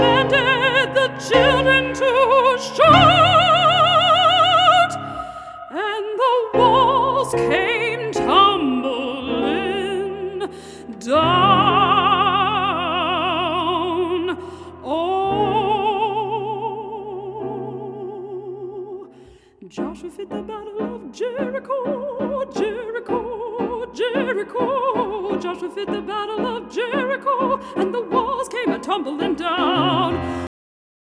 Inspirational With A Classical Twist.